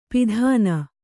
♪ pidhāna